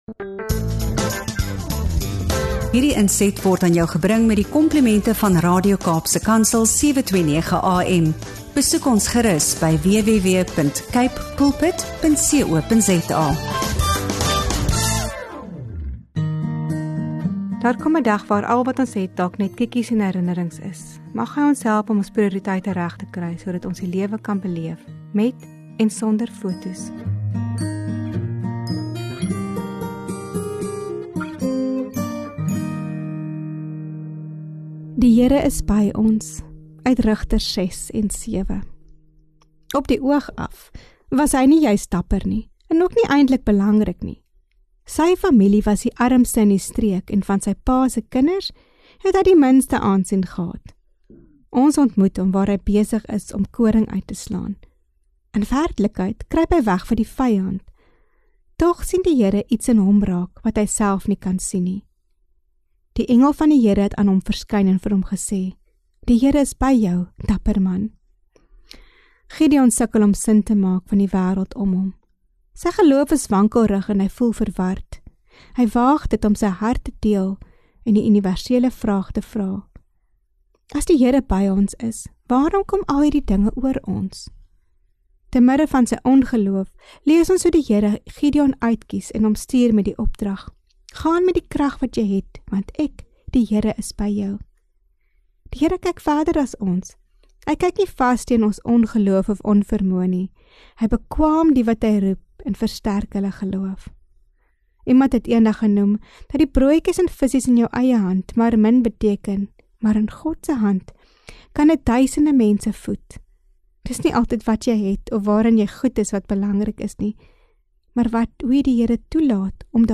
In hierdie diepgaande geloofsgesprek duik ons in Rigters 6 en 7 en die verhaal van Gideon – ’n man vol twyfel, onsekerheid en vrees, maar tog geroep deur God.